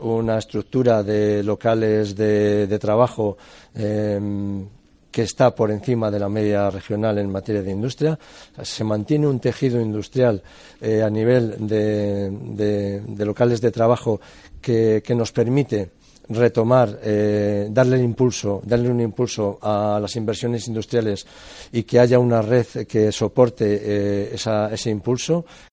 Mariano Veganzones, consejero de Industria, Comercio y Empleo